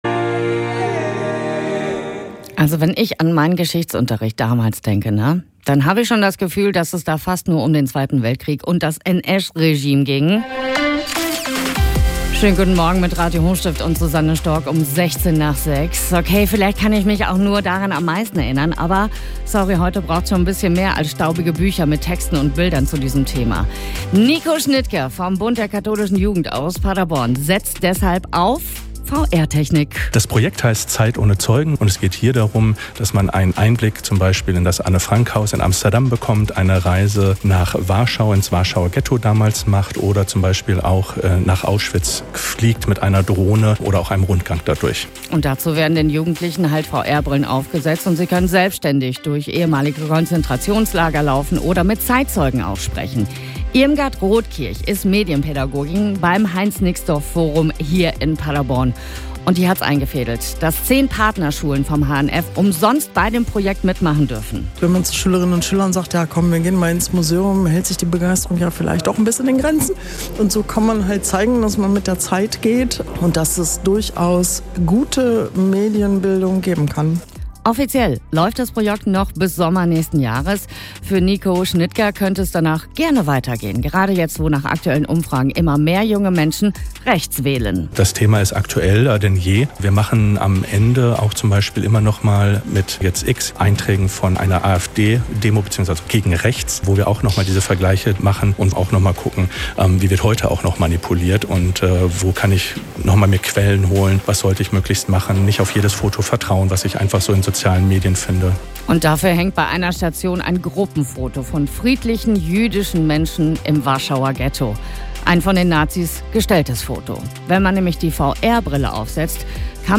In zwei Radiobeiträgen von Radio Hochstift erfährst du mehr über unser Projekt.